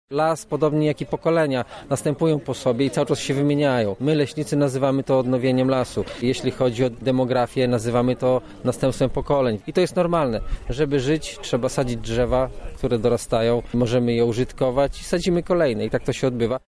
-mówi Andrzej Konieczny, dyrektor generalny lasów państwowych.